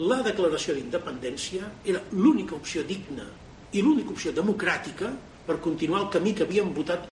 Declaraciones de Puigdemont en Bruselas